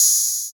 Open Hats
Hat_Open_04.wav